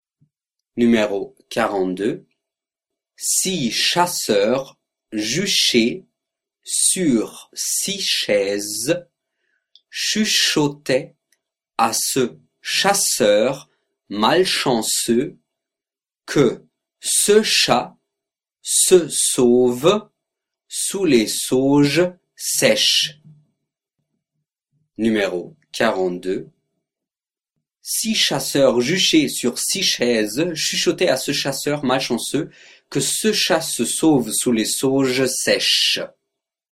42 Virelangue